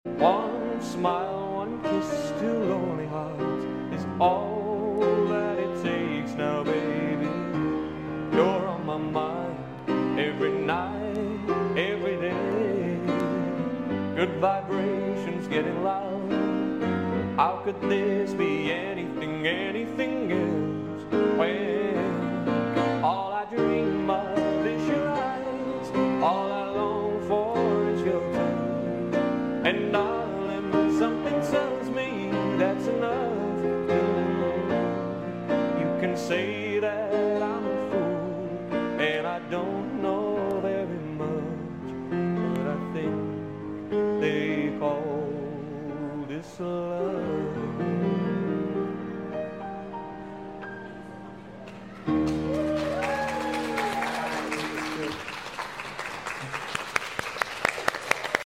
singing live in Singapore